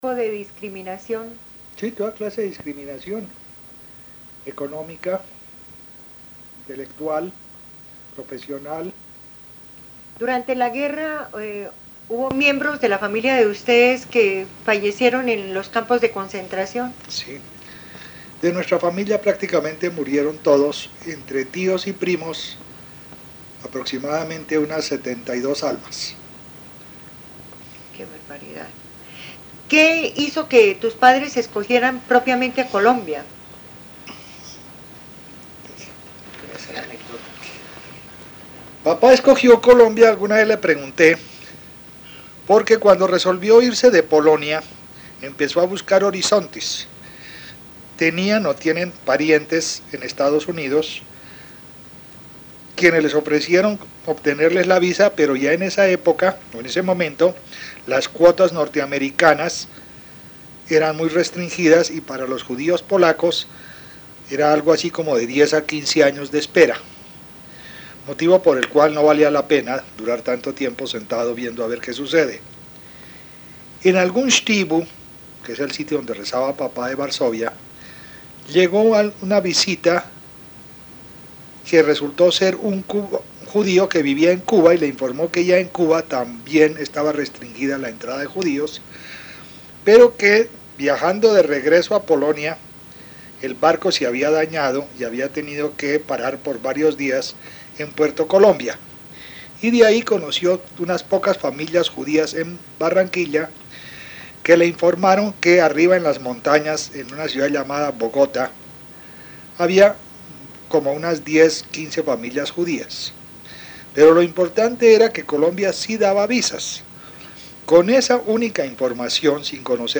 Audio de entrevista en MP3